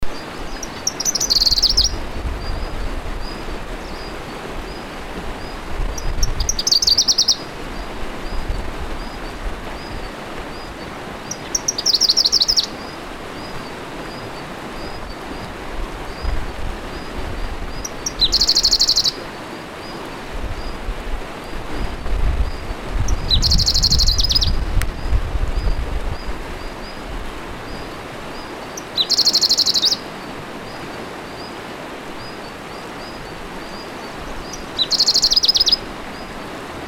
Yellow-streaked Warbler
Feet yellowish-brown, sometimes a bit lighter than Radde’s. VOICE Song very similar to Radde’s, but weaker and often introduced by call; tzic call more bunting-like than similar call of Radde’s and Dusky Warbler P. fuscatus.
warbler-yellow-streaked001-Phylloscopus-armandii.mp3